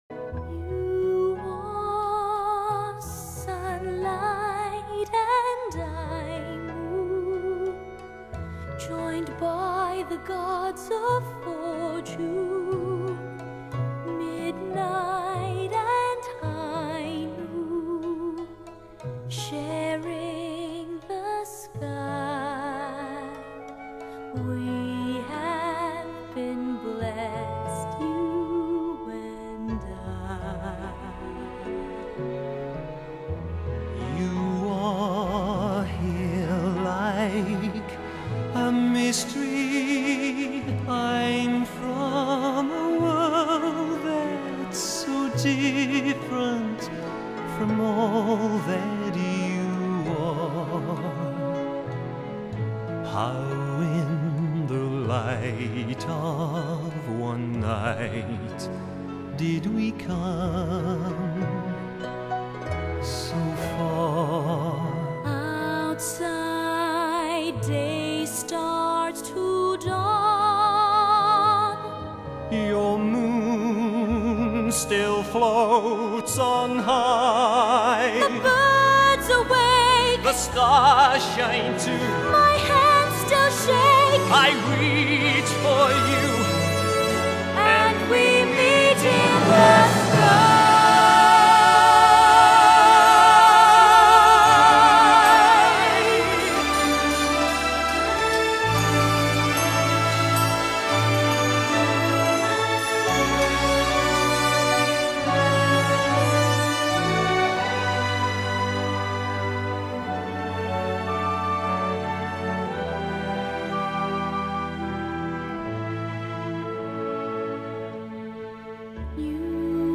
版本特性：Cast Recording